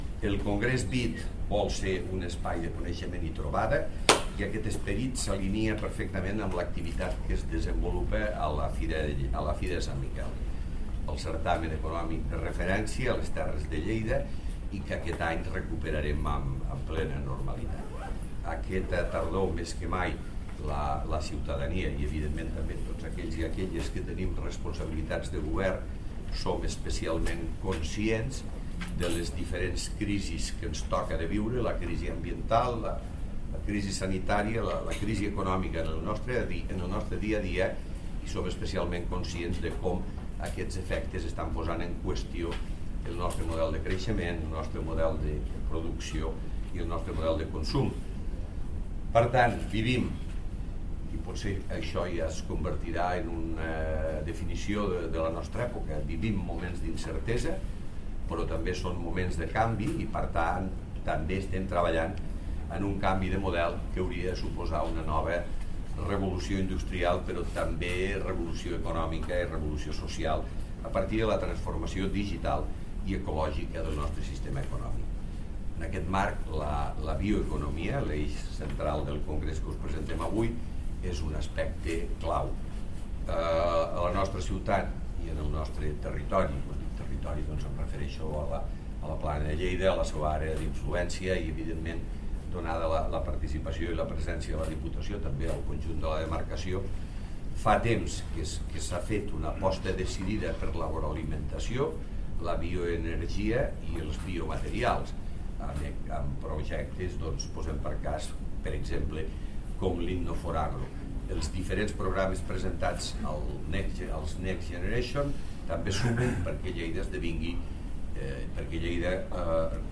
tall-de-veu-m-pueyo